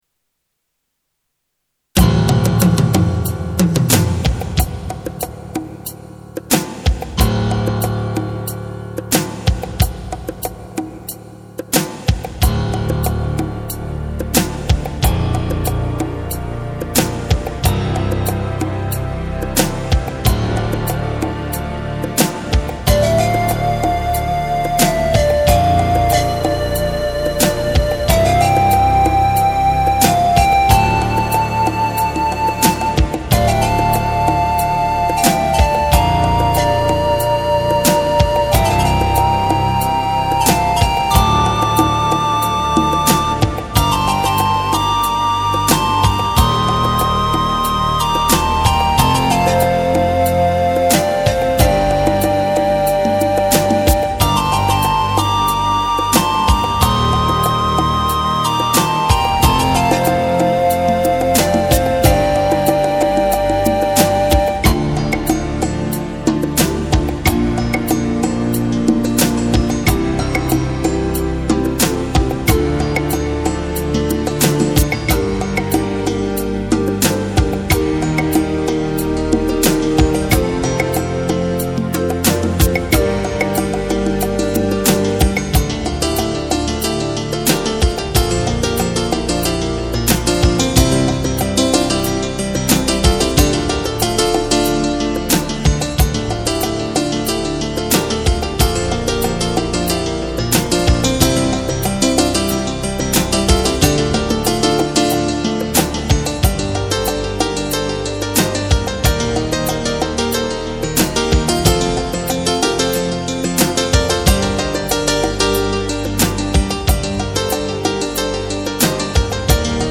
Recorded in MIDI or MDD technloge.